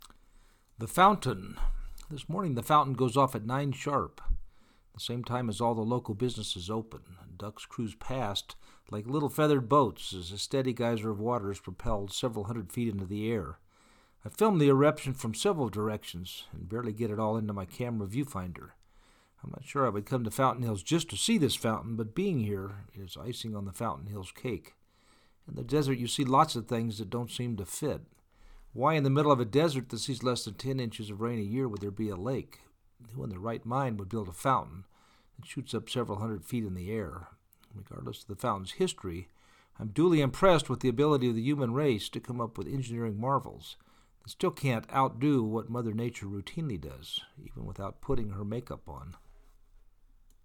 Ducks cruise past it like little feathered boats as a steady geyser of water is propelled several hundred feet into the air.  I film the eruption from several directions and barely get it all into my camera viewfinder.
the-fountain-2.mp3